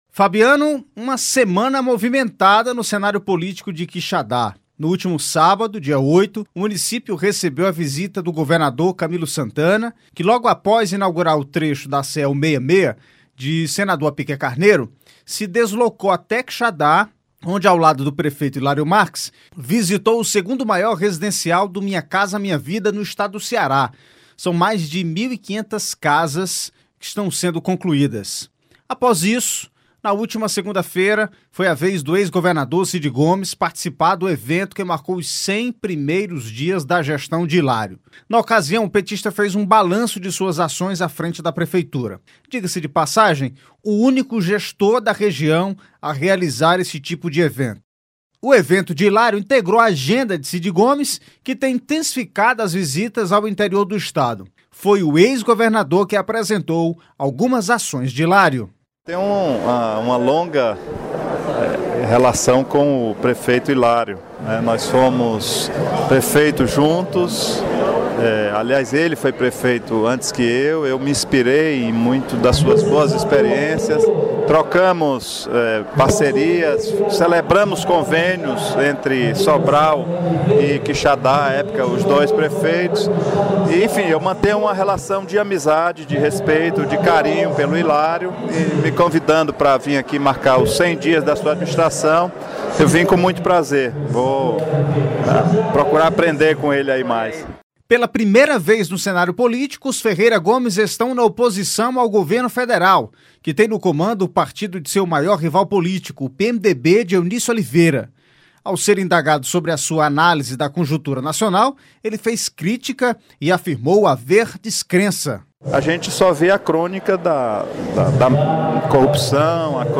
Entrevista-de-Cid-Gomes.mp3